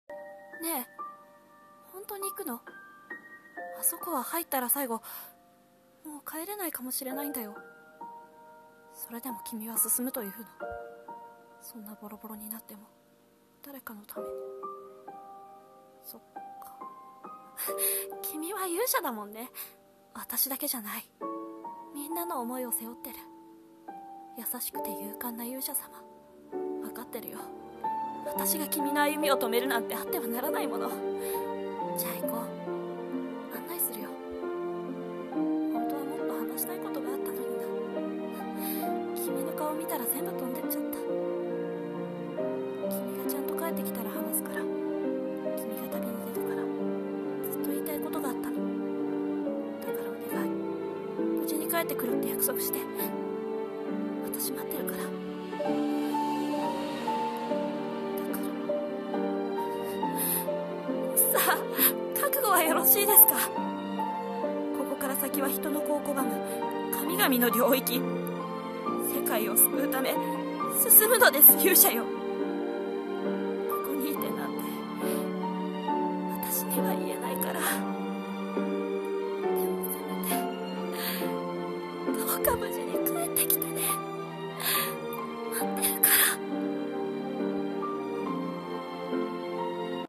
【一人声劇】NPCの想い【台本】